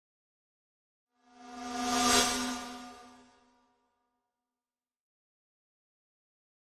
Static Buzz By Thin Buzz, Creepy Pass - Version 1